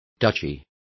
Complete with pronunciation of the translation of duchy.